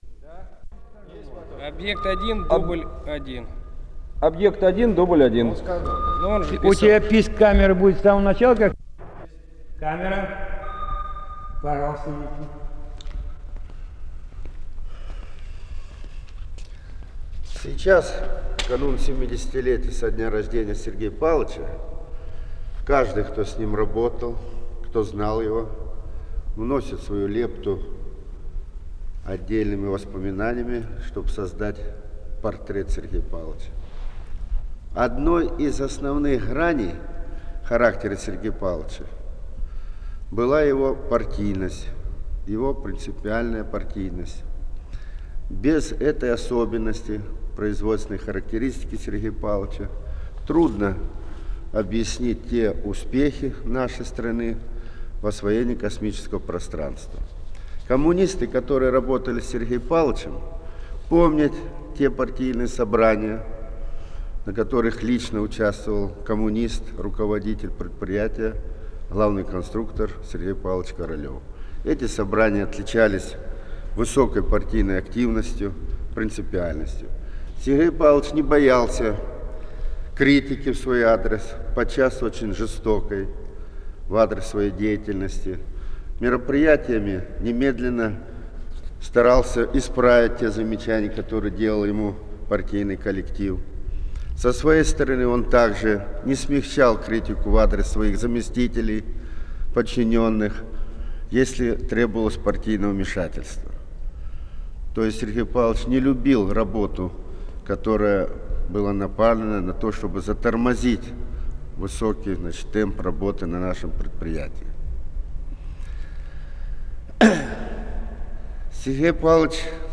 с профессиональной магнитной ленты
Название передачиИнтервью к 70-летию со дня рождения академика С. П. Королёва
СодержаниеСъёмки в демонстрационном зале
Скорость ленты9 см/с
ВариантМоно